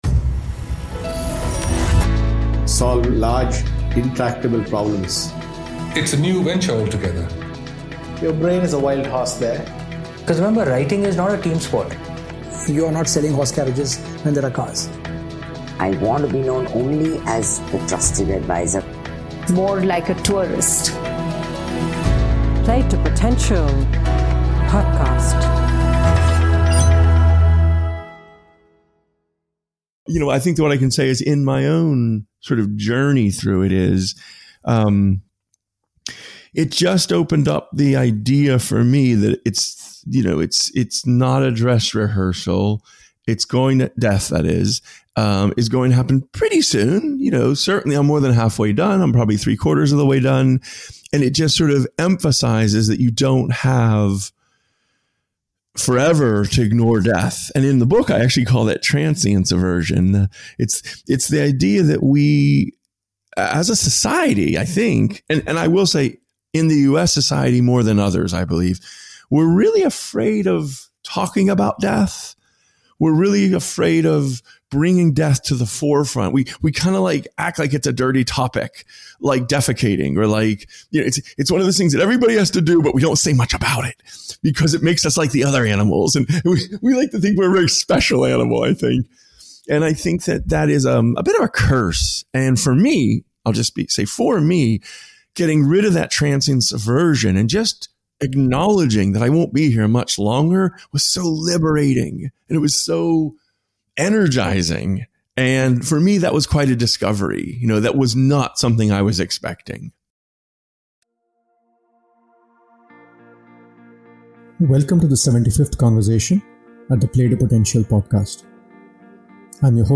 In our conversation, we spoke about his journey including a cancer diagnosis which led him to examine his life and make pivotal choices.